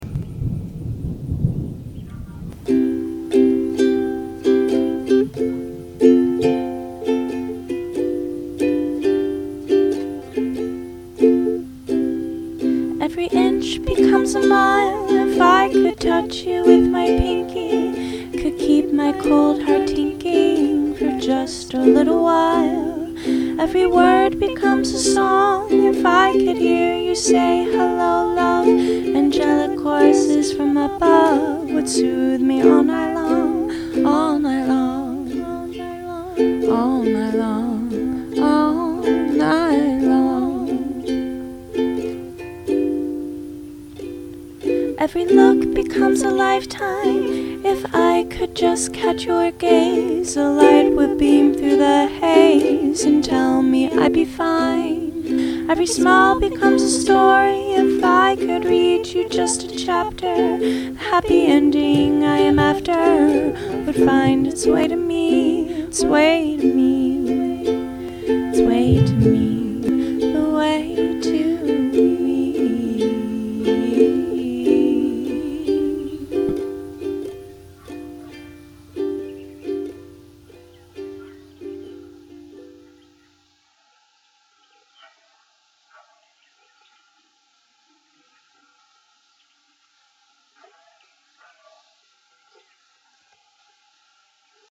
I took a vacation to the country last week and recorded a first version of this song outside during a thunderstorm.
To me,this is a little love song written for an unknown person.